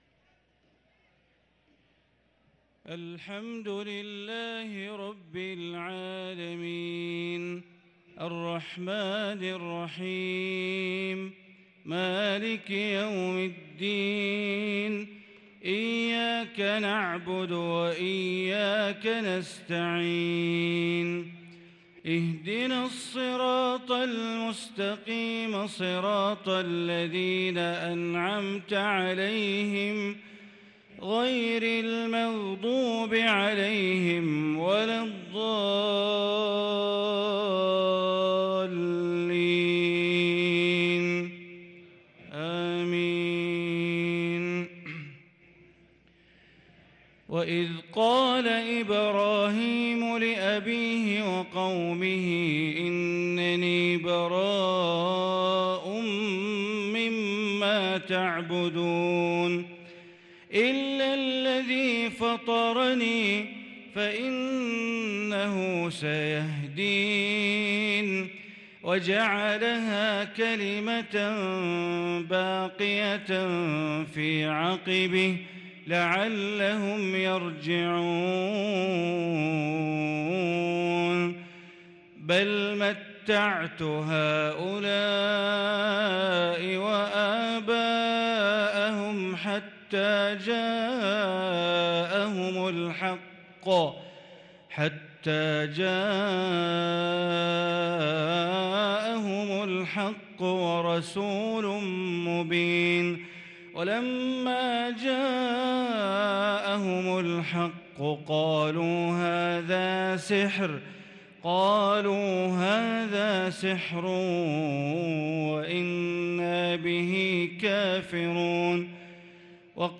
صلاة العشاء للقارئ بندر بليلة 15 ذو الحجة 1443 هـ
تِلَاوَات الْحَرَمَيْن .